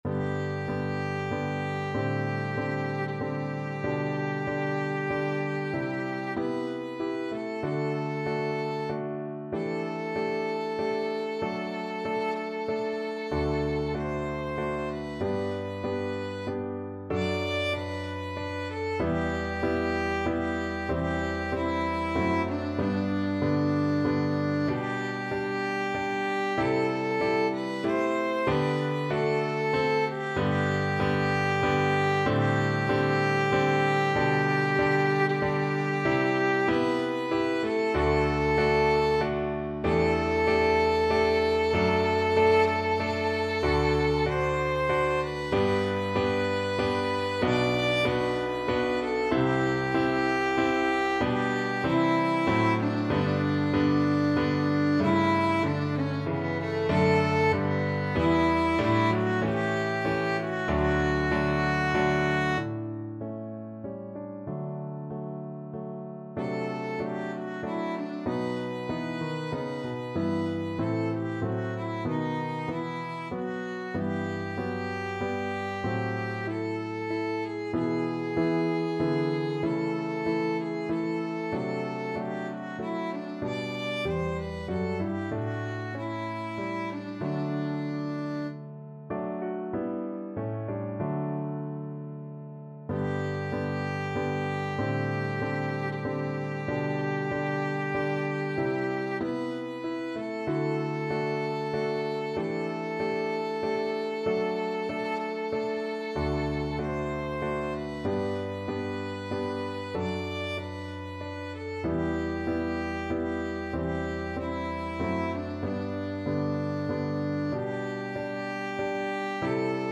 Classical Handel, George Frideric Thanks Be to Thee (Dank sei dir Herr) Violin version
Violin
3/4 (View more 3/4 Music)
G major (Sounding Pitch) (View more G major Music for Violin )
=95 Andante
thanks_be_to_thee_VLN.mp3